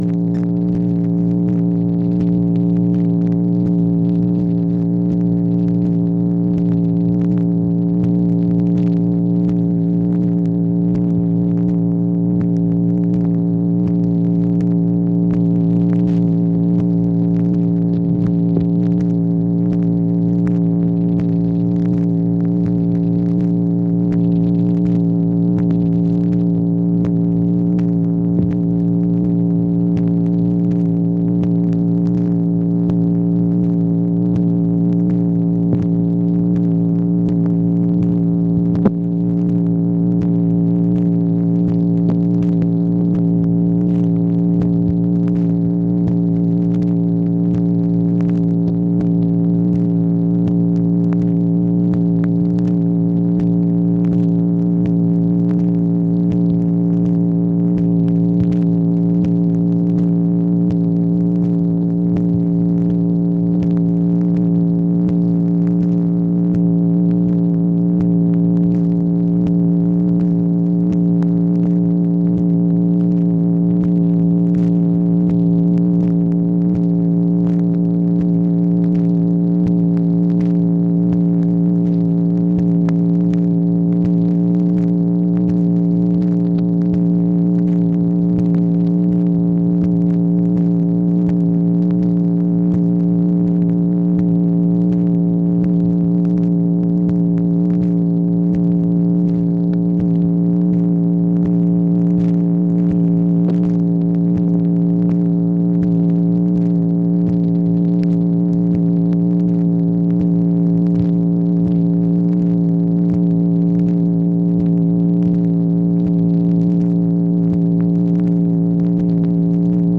MACHINE NOISE, June 29, 1964
Secret White House Tapes | Lyndon B. Johnson Presidency